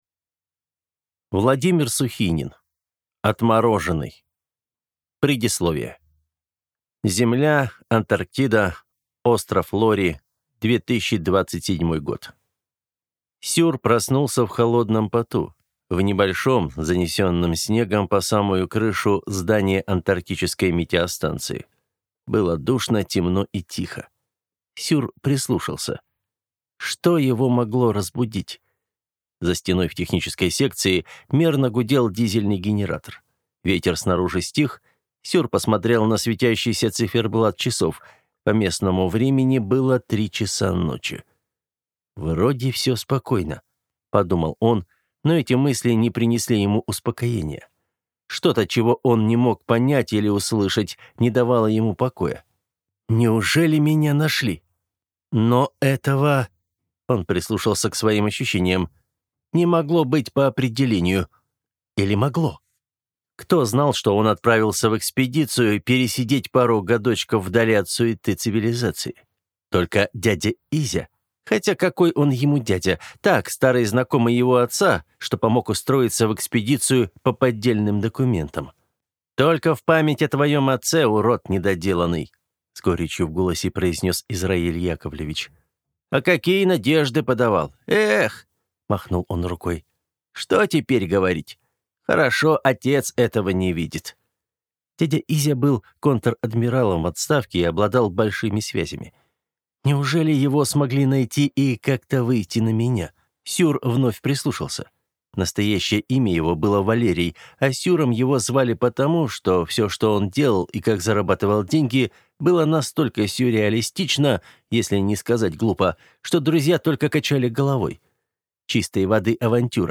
Аудиокнига Отмороженный. Книга 1 | Библиотека аудиокниг